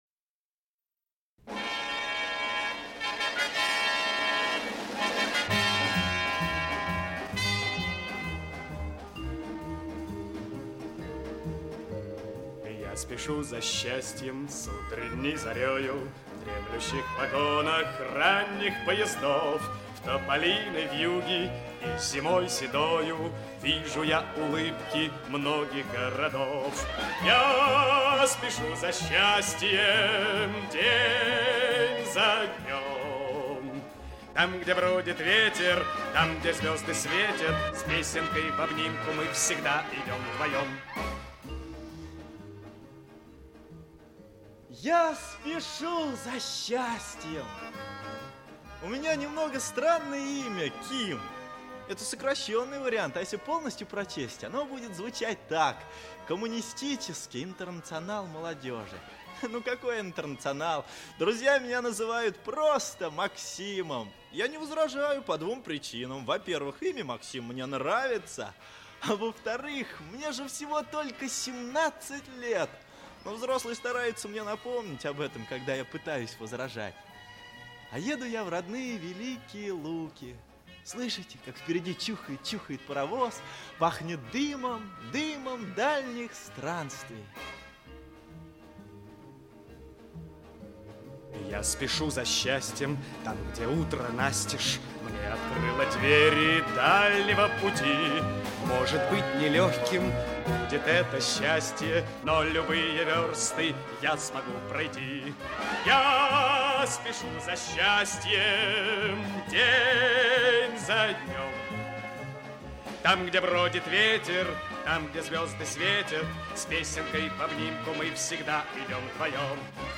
Аудиокнига Я спешу за счастьем | Библиотека аудиокниг
Aудиокнига Я спешу за счастьем Автор Вильям Козлов Читает аудиокнигу Лев Дуров.